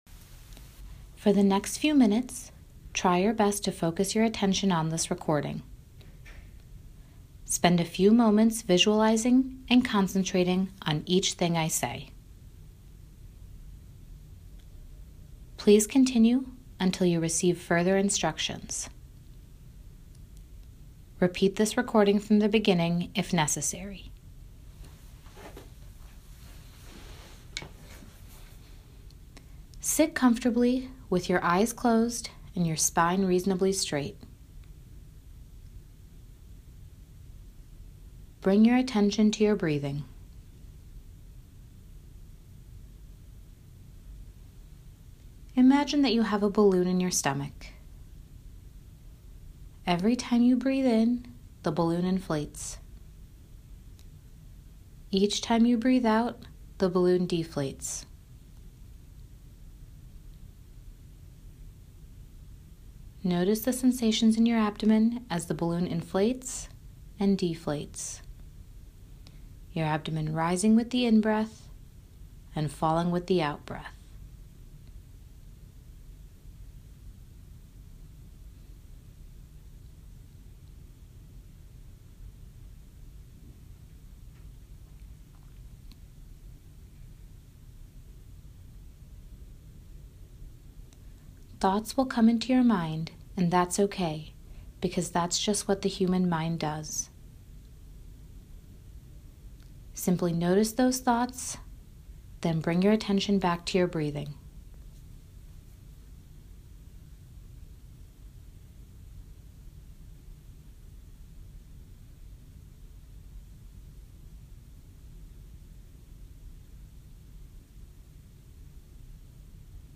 Example Mindful Relaxation Recording
mindful relaxation.mp3